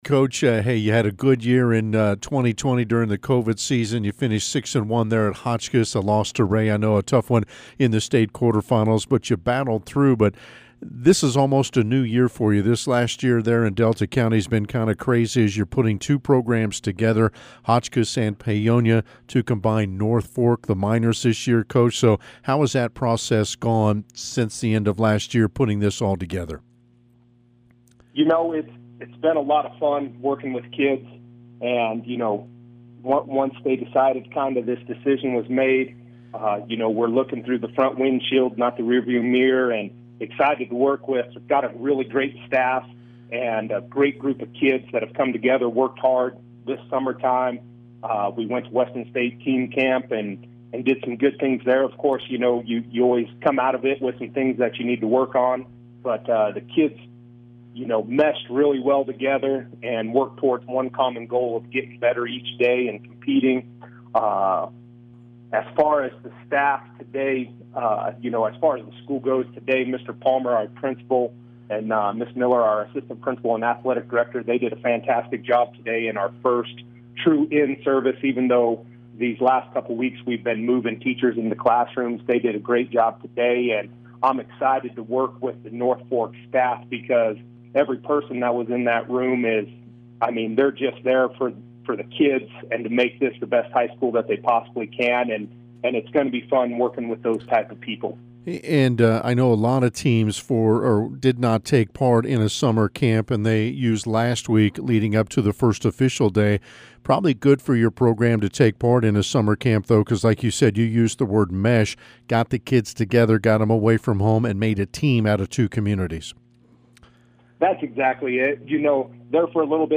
1A Football Pre-Season Interviews - Colorado Preps